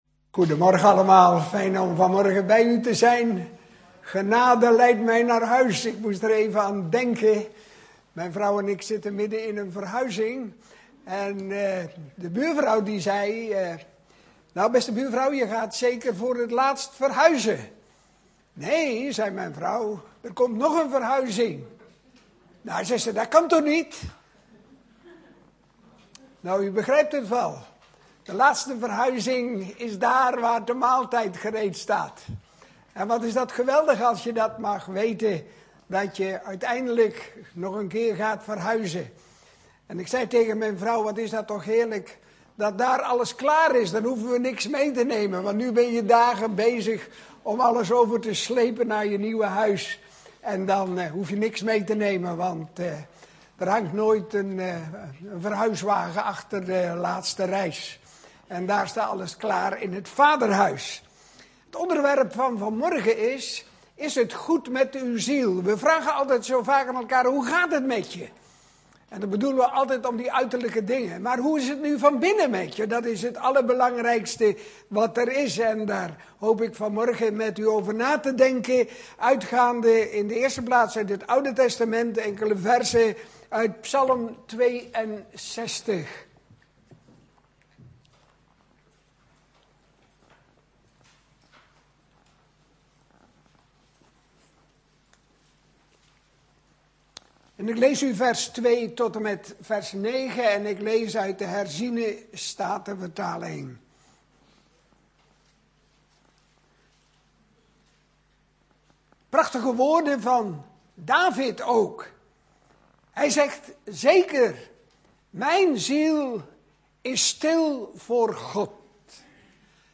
In de preek aangehaalde bijbelteksten (Statenvertaling)Psalmen 62:1-81 Een psalm van David, voor den opperzangmeester, over Jeduthun.